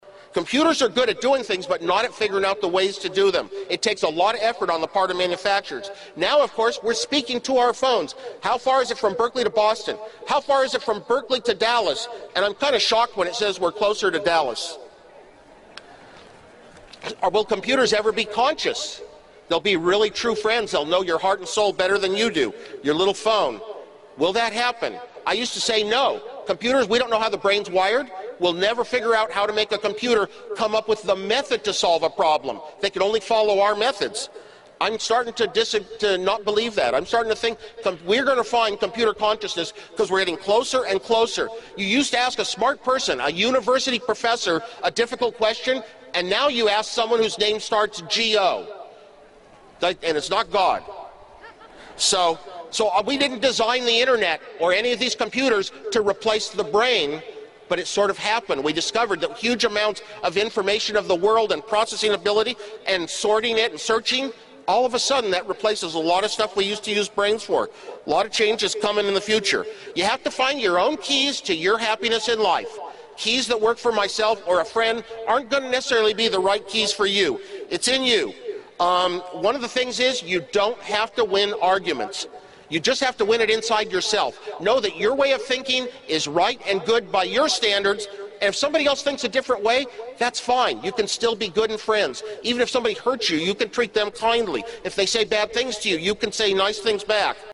公众人物毕业演讲 第151期:史蒂夫·沃兹尼亚克于加州大学伯克利分校(8) 听力文件下载—在线英语听力室